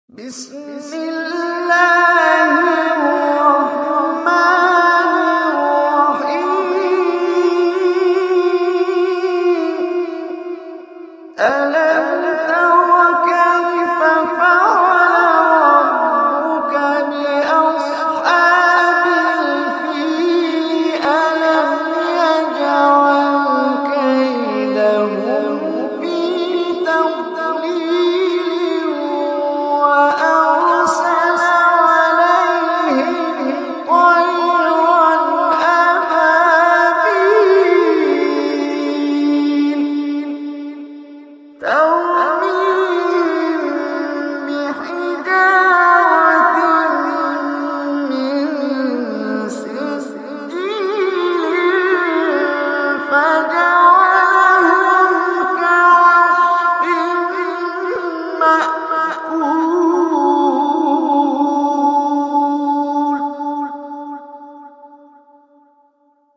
Surah Al Fil Recitation